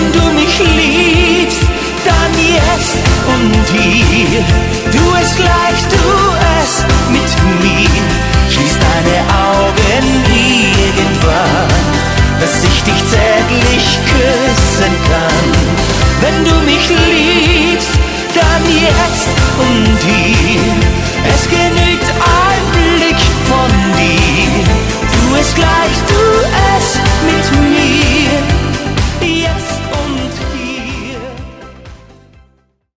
Die Schlagerband mit Herz für jeden Anlass